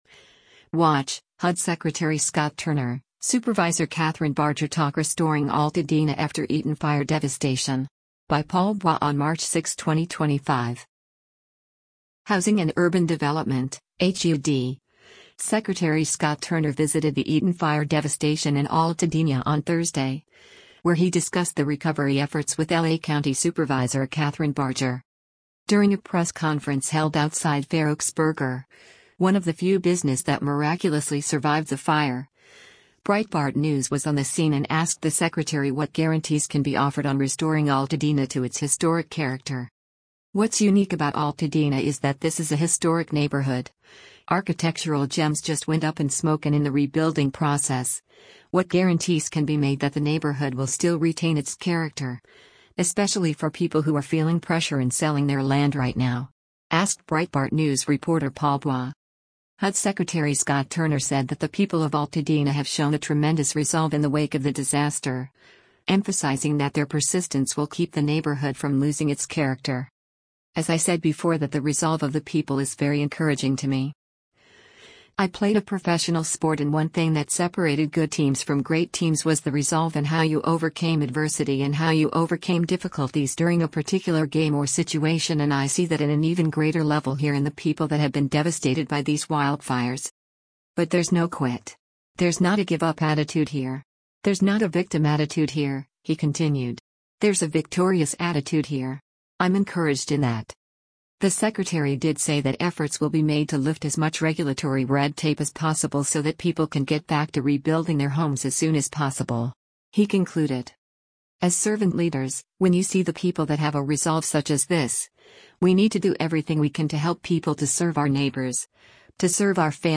During a press conference held outside Fair Oaks Burger – one of the few business that miraculously survived the fire – Breitbart News was on the scene and asked the secretary what guarantees can be offered on restoring Altadena to its historic character.